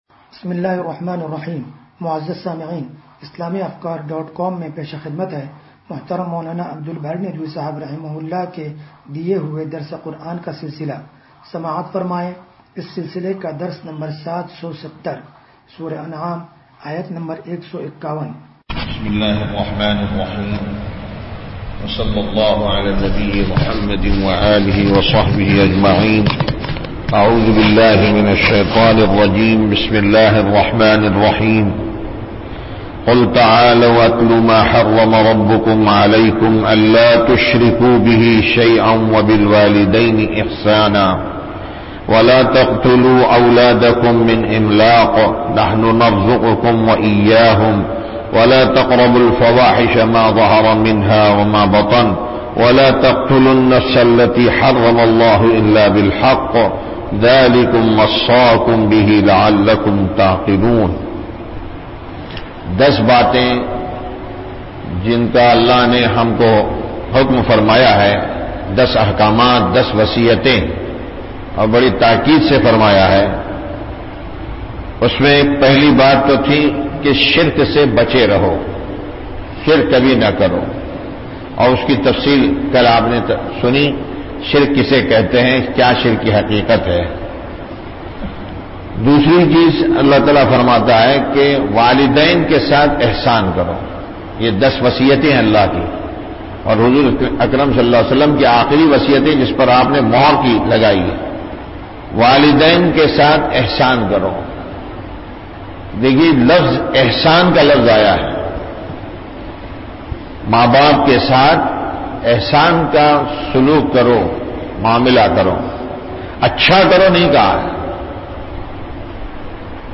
درس قرآن نمبر 0770
درس-قرآن-نمبر-0770.mp3